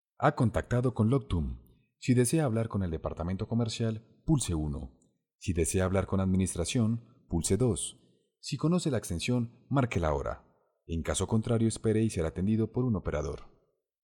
Ingeniero de profesion, capacitado en doblaje y locucion, participacion en fandubs, voz grave natural, con matices e interpretacion para alcanzar varios tonos.
kolumbianisch
Sprechprobe: eLearning (Muttersprache):